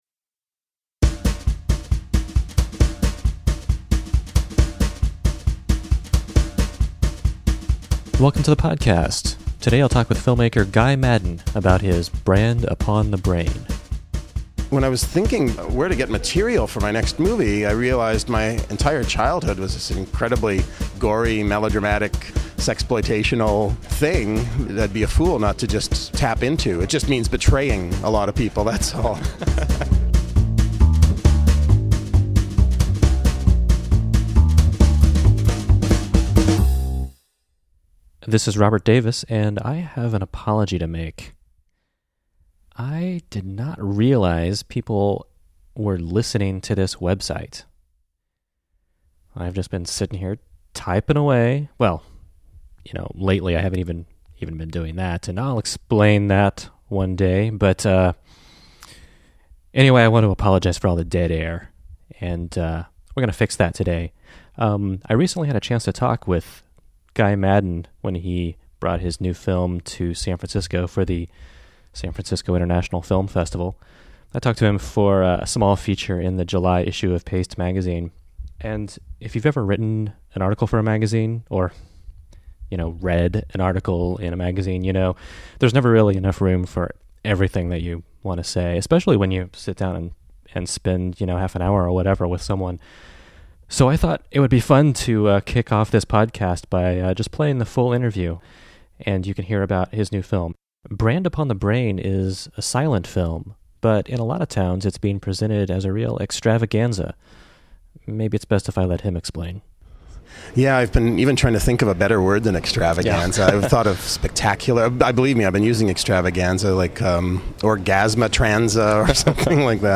Welcome to the very first episode of the Errata podcast, an audio program of movie chit-chat. This first episode features a conversation with filmmaker Guy Maddin about his new silent film, Brand Upon the Brain!, which is being presented in various cities this summer as a live extravaganza, with an orchestra, foley artists, a soloing castrato, and a different celebrity interlocutor at each stop.